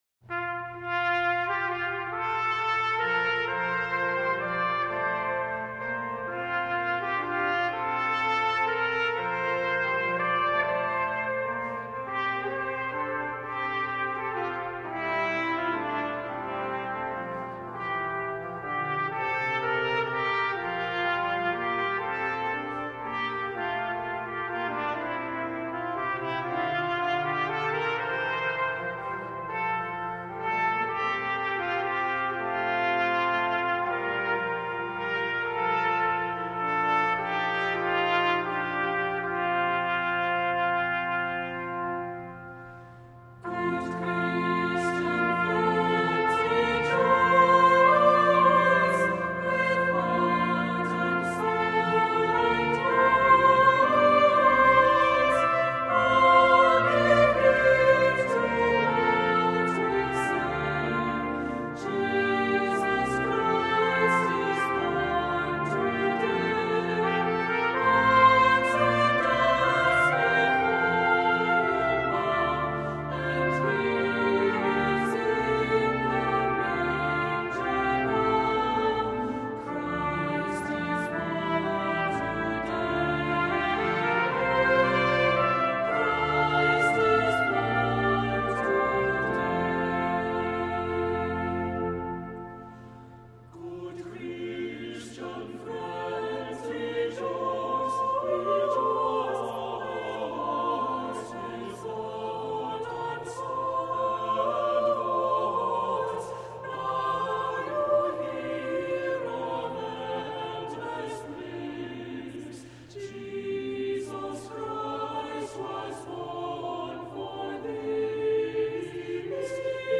Accompaniment:      Organ, Trumpet in B-flat;Trumpet in C
Music Category:      Choral